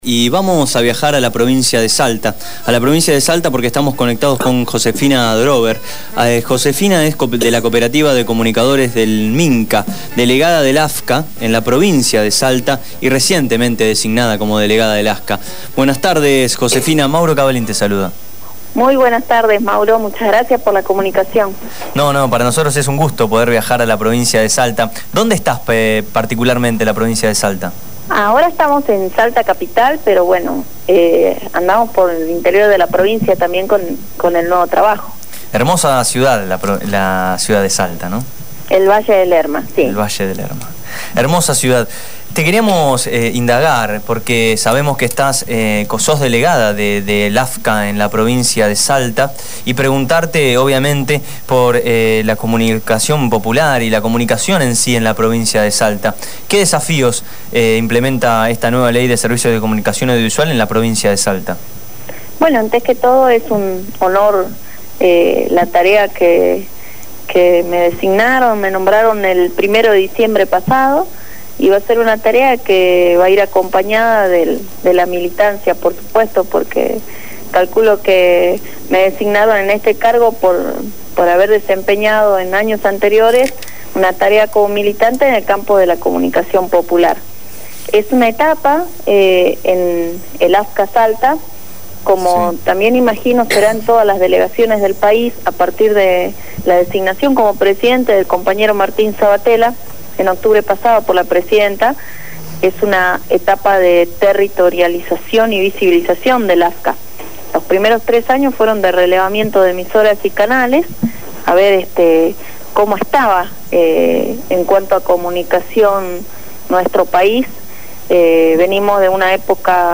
Josefina Adrover, delegada de la Autoridad Federal de Servicios de Comunicación Audiovisual de la Provincia de Salta designada el 1 de diciembre último, habló en el programa Abramos la Boca.